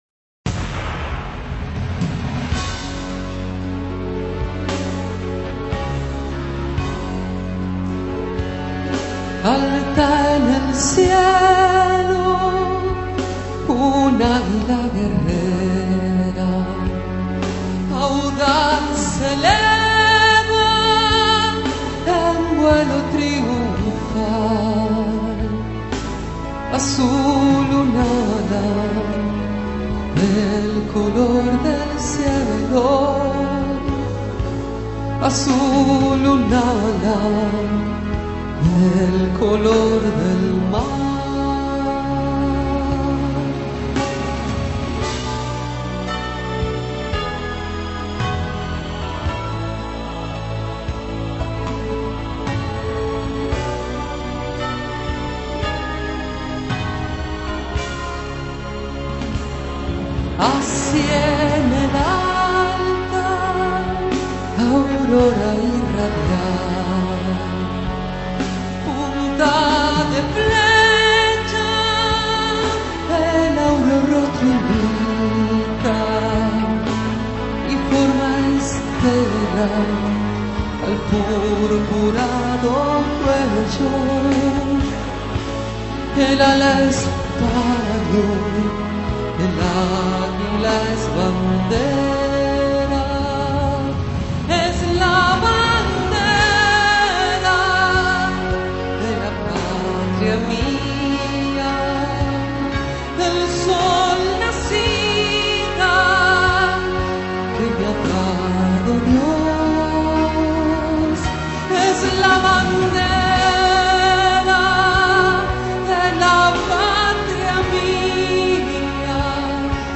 voz
pista musical